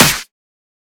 edm-clap-43.wav